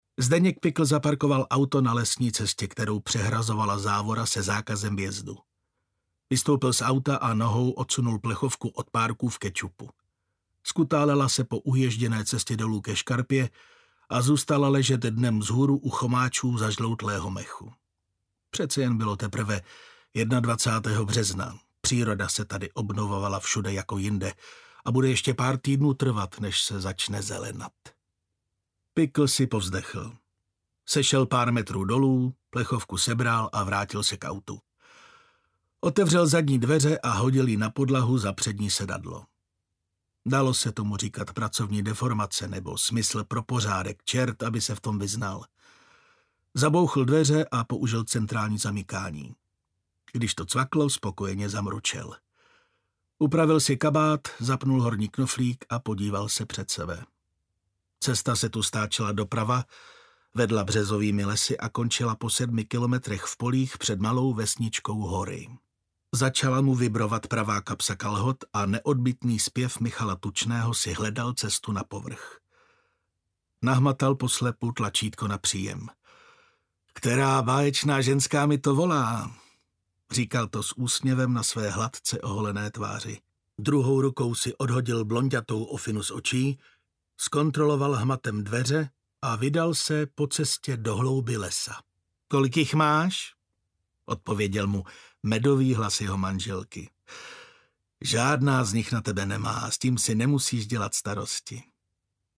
Letargie audiokniha
Ukázka z knihy